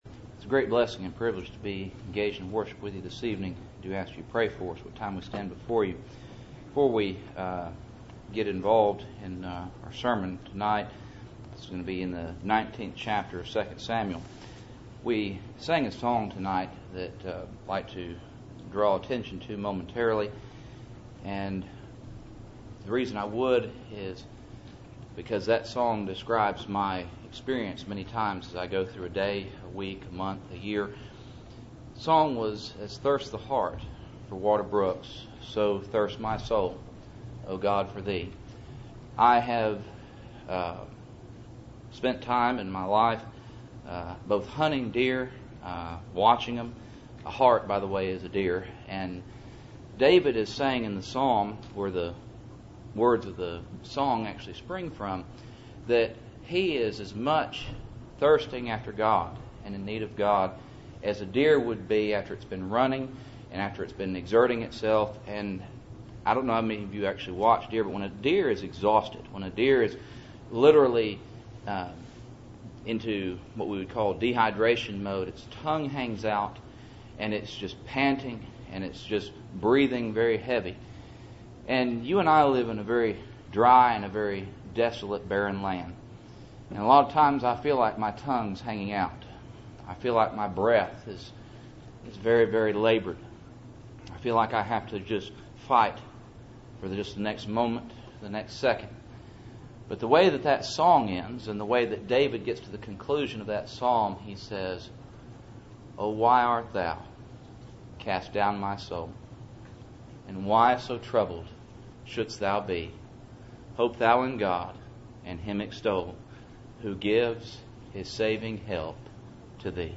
Passage: 2 Samuel 19:1-14 Service Type: Cool Springs PBC Sunday Evening